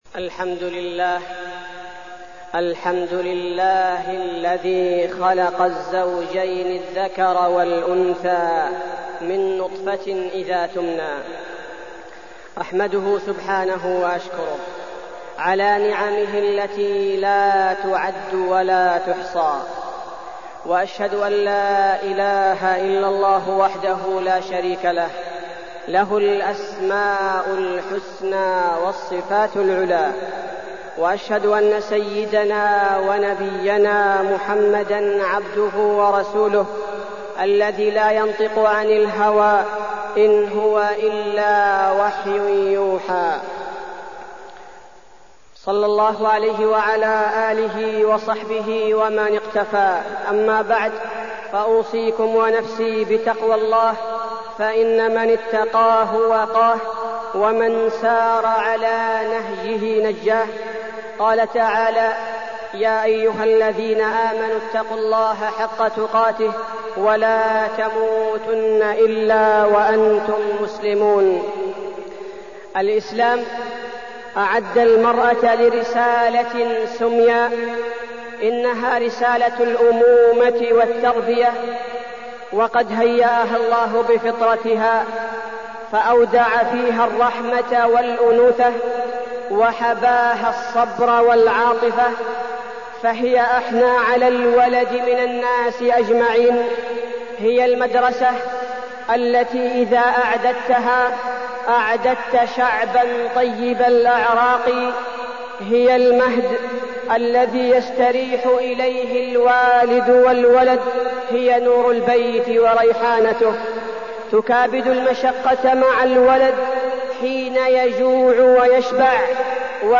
تاريخ النشر ٦ صفر ١٤٢٠ هـ المكان: المسجد النبوي الشيخ: فضيلة الشيخ عبدالباري الثبيتي فضيلة الشيخ عبدالباري الثبيتي المرأة